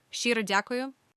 SHCHY-roh DYAH-koo-yoo I sincerely thank you